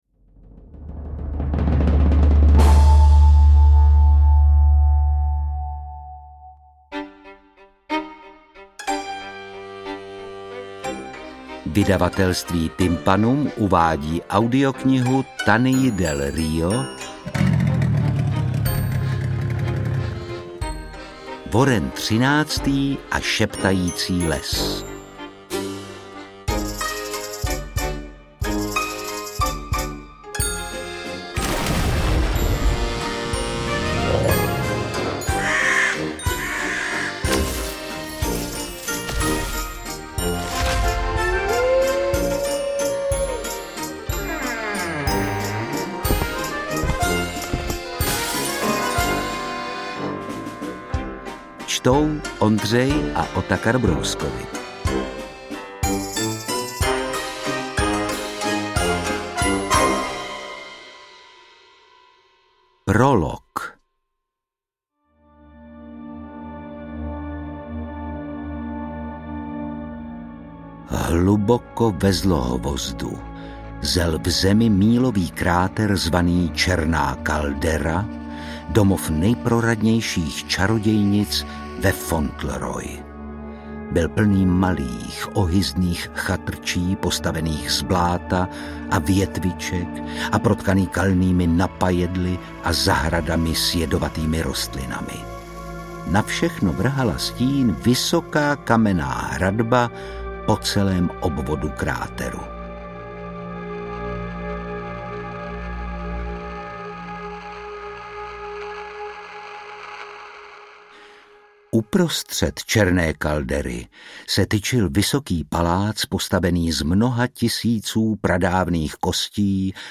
Interpreti:  Ondřej Brousek, Otakar Brousek
AudioKniha ke stažení, 21 x mp3, délka 5 hod. 40 min., velikost 311,0 MB, česky